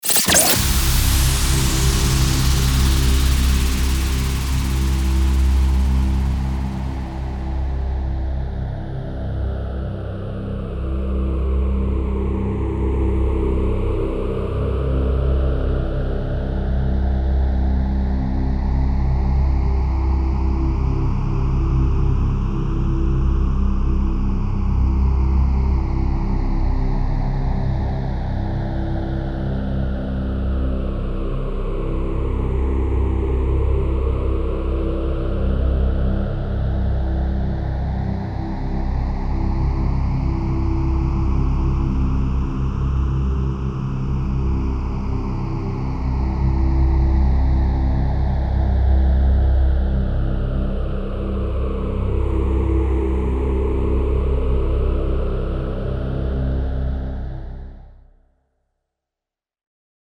DRONE-030-ONBOARDING-THE-SHIP-121BPM-C